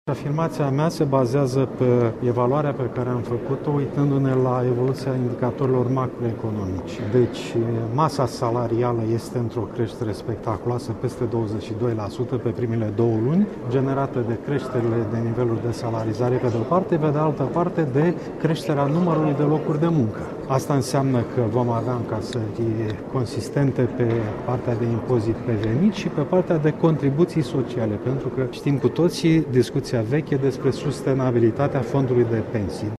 Așa a anunţat ministrul Finanţelor, Viorel Ştefan. El a mai adăugat, însă, că abia la finalul acestei luni se va putea vedea dacă prognoza financiară este în concordanţă cu programul de guvernare: